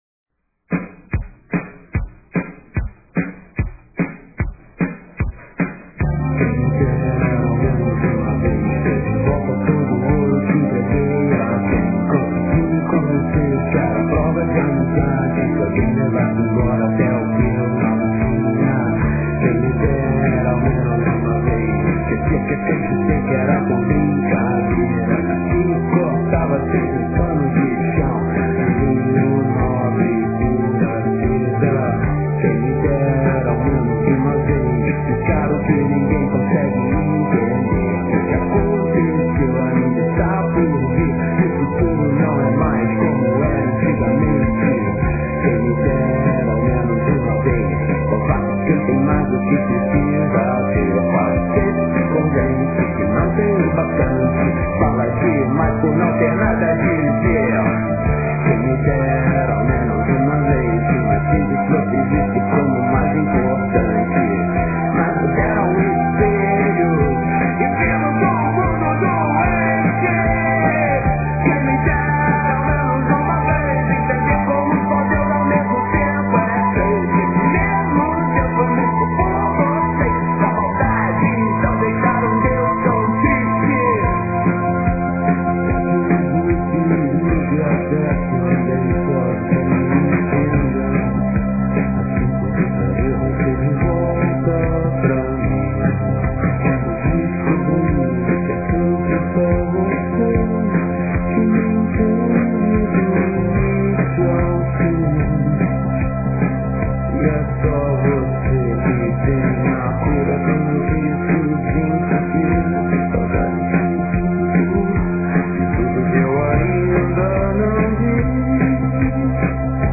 no final tem um Link para Abrir a Música que é Cantada.